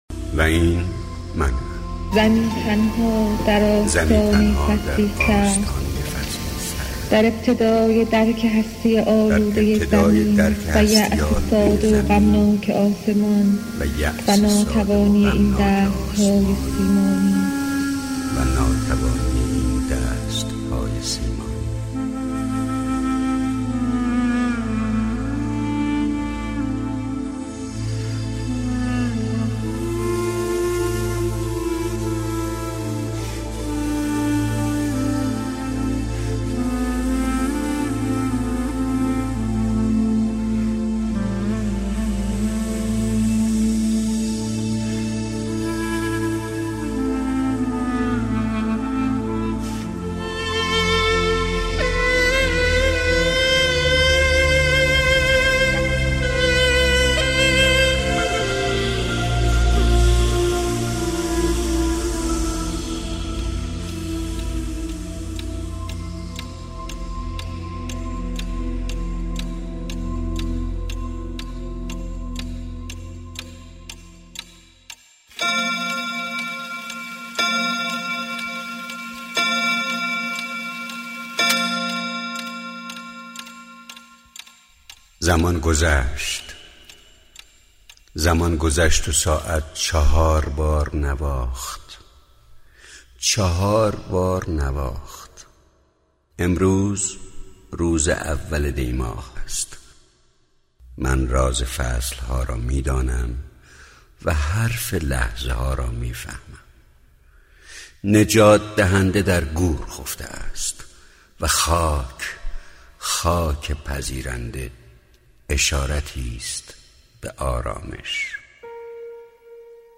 دانلود دکلمه ایمان بیاوریم با صدای خسرو شکیبایی با متن دکلمه